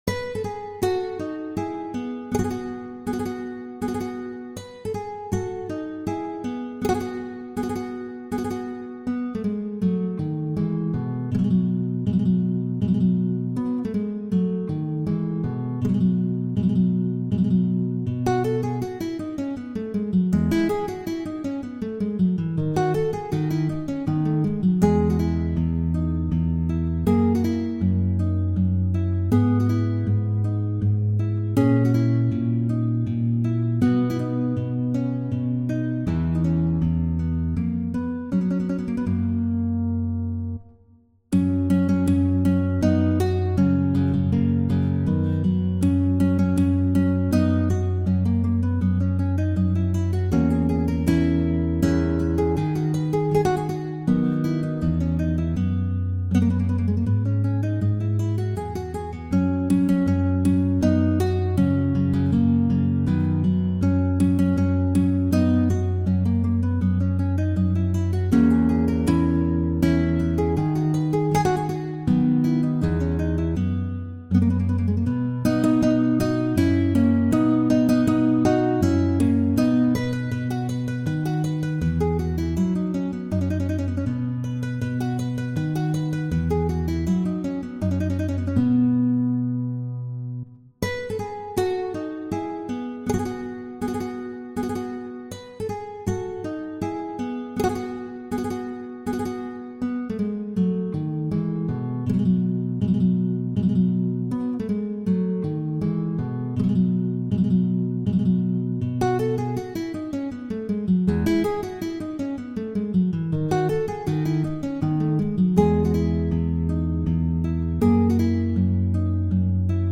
Sonate en mi majeur (K380).pdf
Sonate-en-mi-majeur-K380.mp3